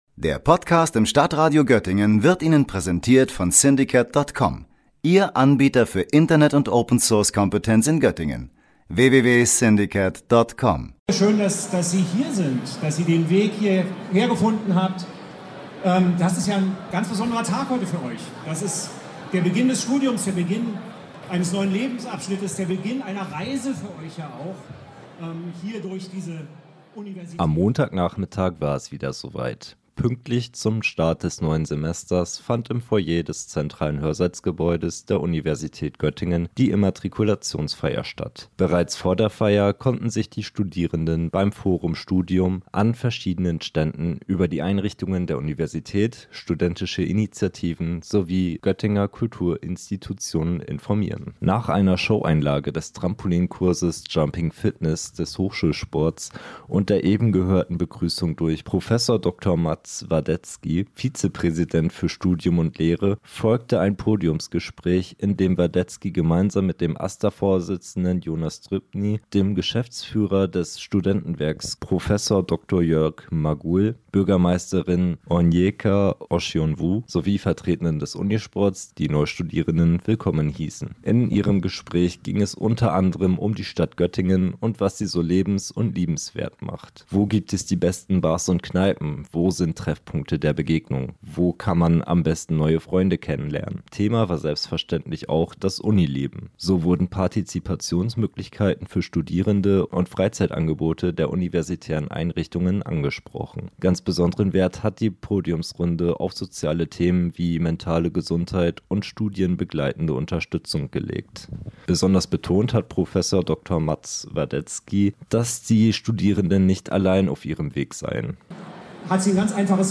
Die O-Phase ist vorbei, nun beginnt der ernst des Unialltags für die neuen Erstsemesterstudenten im Wintersemester 2025/26. Zur Feier des Tages und um das Studierendenleben gebührend zu starten, fand am Montag die Immatrikulationsfeier am Zentralcampus der Georg-August-Universität statt.